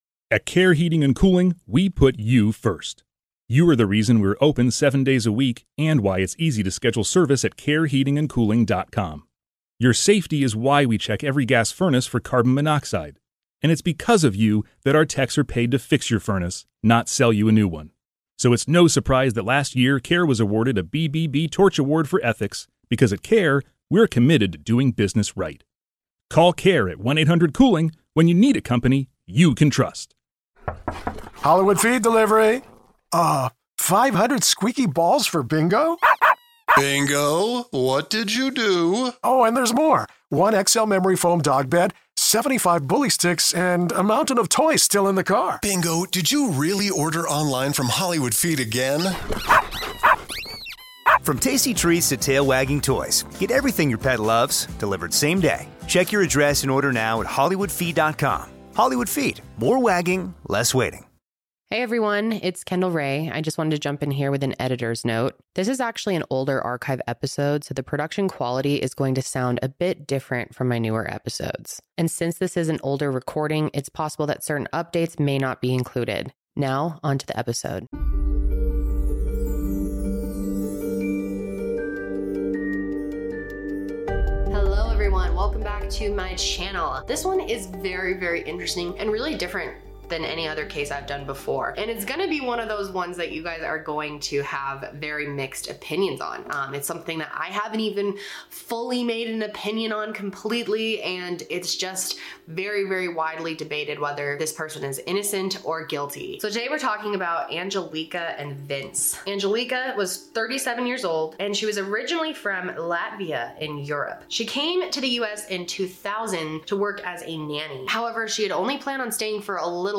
Archive episode disclaimer: This is an older archive episode, so the production quality is going to sound different compared to newer episodes.